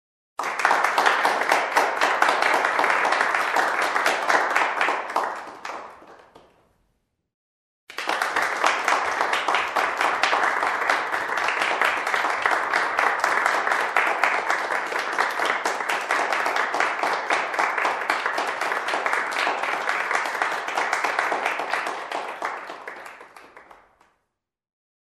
Звуки аудитории, толпы
Зрители рукоплещут